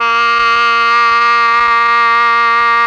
RED.OBOE  16.wav